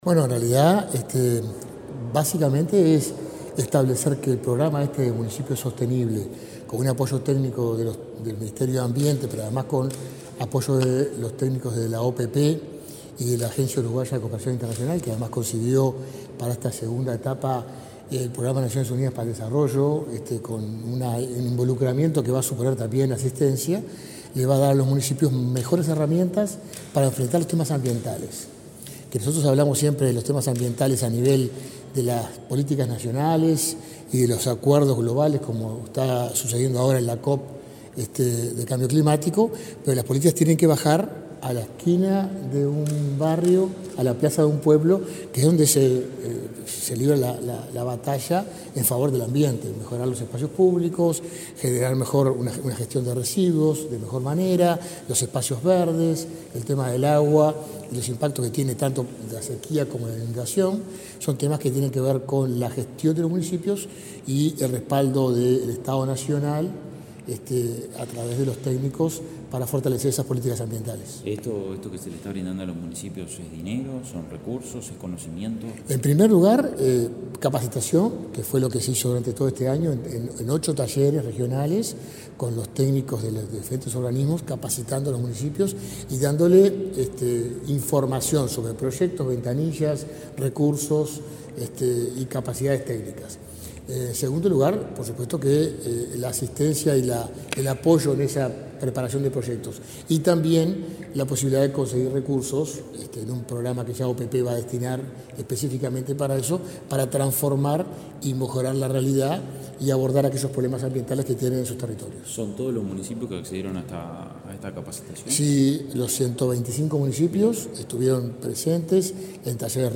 Declaraciones del subsecretario de Ambiente, Gerardo Amarilla
Declaraciones del subsecretario de Ambiente, Gerardo Amarilla 12/12/2023 Compartir Facebook Twitter Copiar enlace WhatsApp LinkedIn El subsecretario de Ambiente, Gerardo Amarilla, dialogó con la prensa en la Torre Ejecutiva, antes de participar en el encuentro de cierre de Municipios Sostenibles.